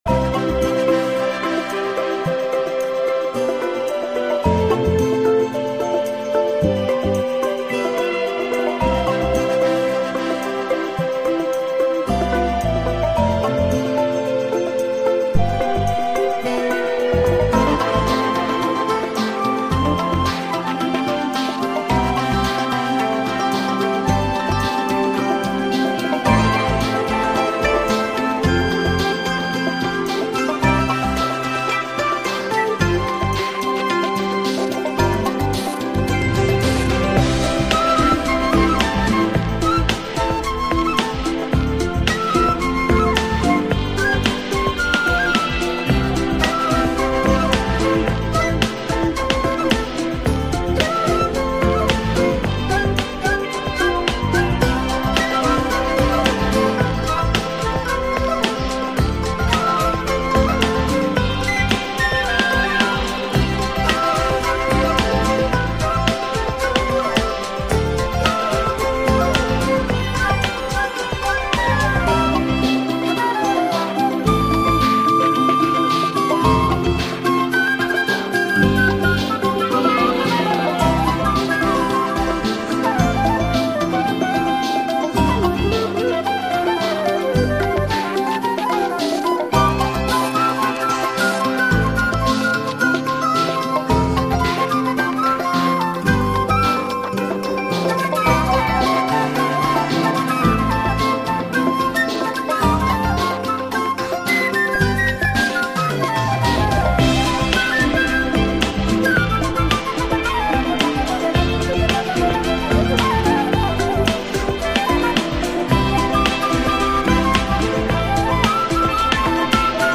OST